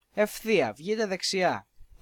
(male)